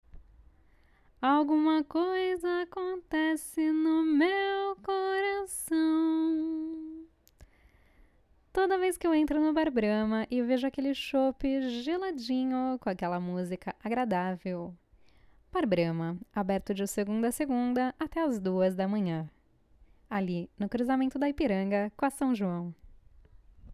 Sweet voice, good for sales, serious.
Sprechprobe: Werbung (Muttersprache):